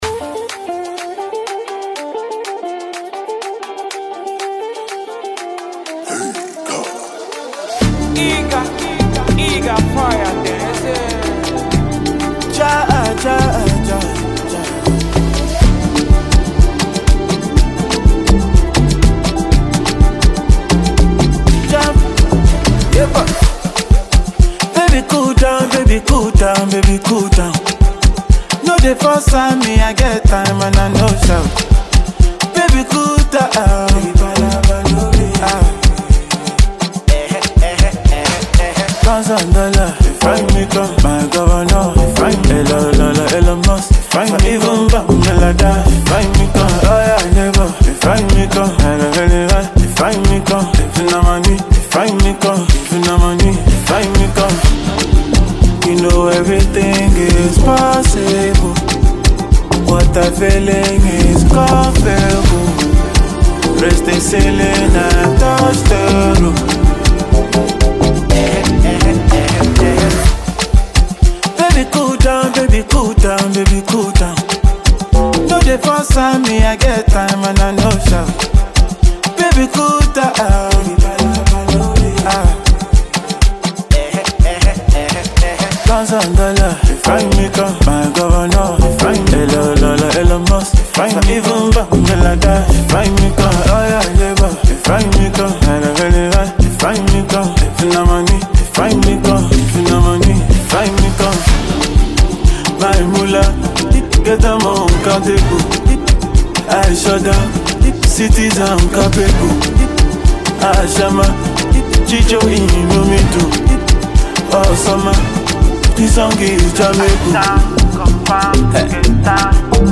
a danceable jam